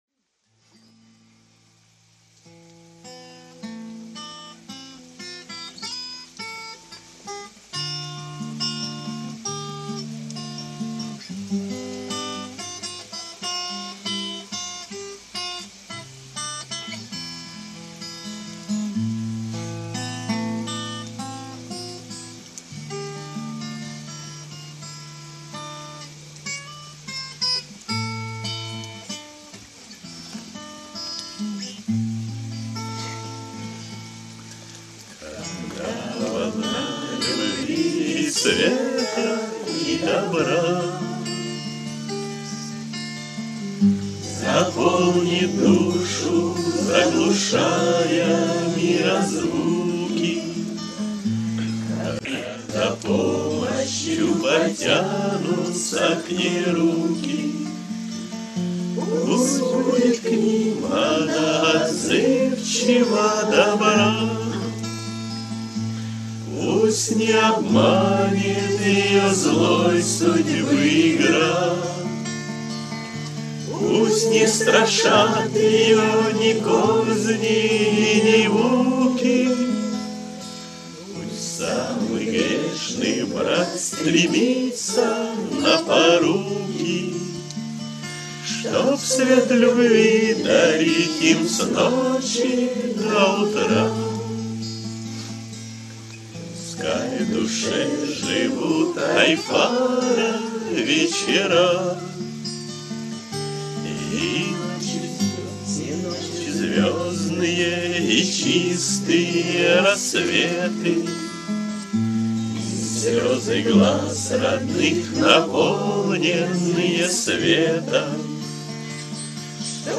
кавер-версия
акапелла
Песни у костра